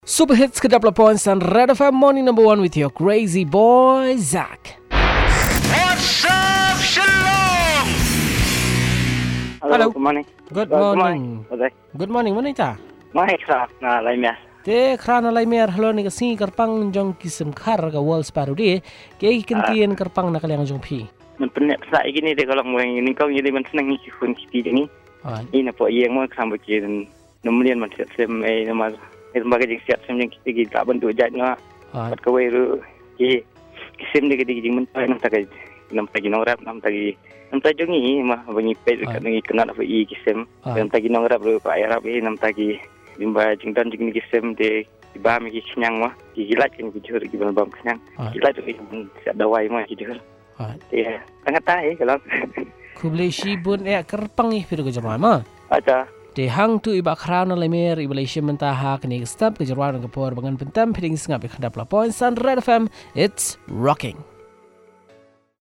Caller – We need to conserve and protect them, not only that they are beautiful to look at but actually they help on keeping the insects and bugs away – hence helping the farmers as well.